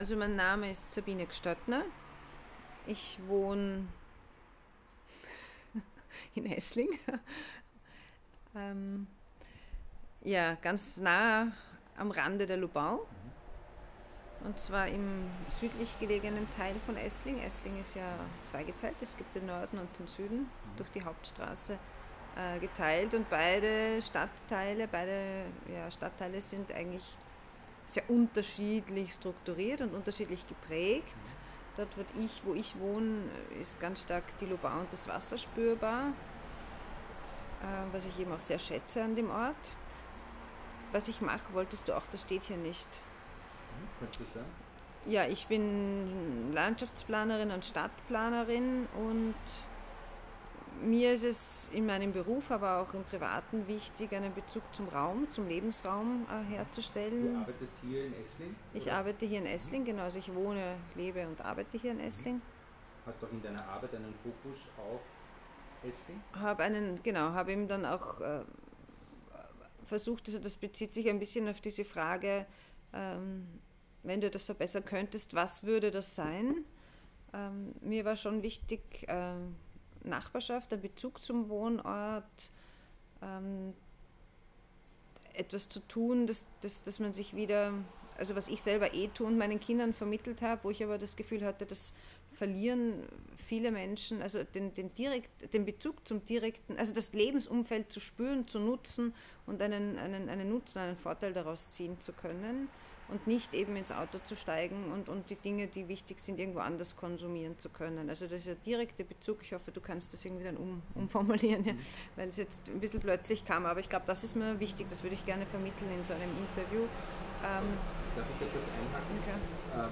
Das ganze Interview